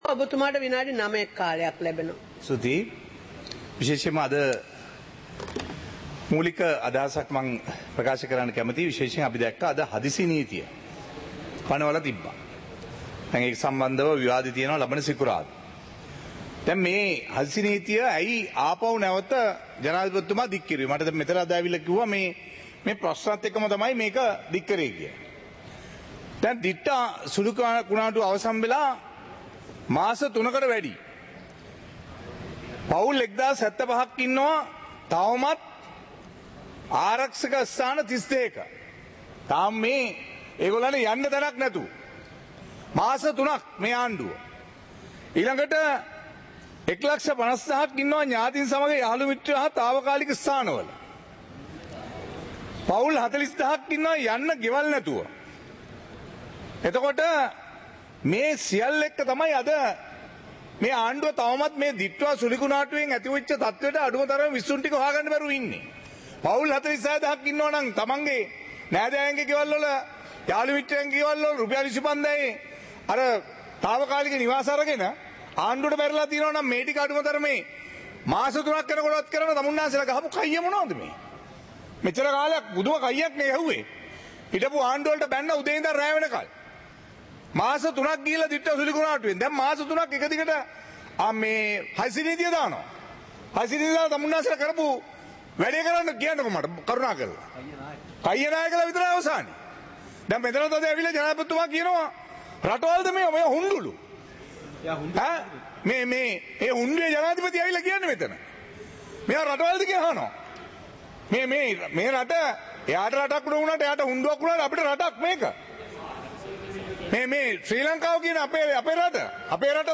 சபை நடவடிக்கைமுறை (2026-03-03)
நேரலை - பதிவுருத்தப்பட்ட